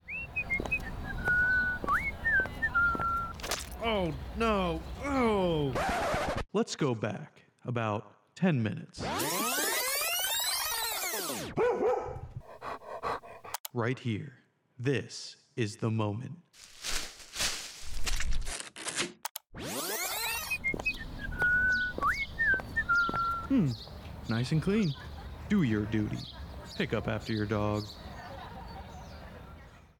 Clean Up After Your Dog Radio Spot, Marine Corps Air Station Iwakuni, Japan 2026